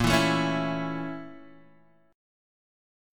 A/Bb chord
A-Major-Bb-x,1,2,2,2,0.m4a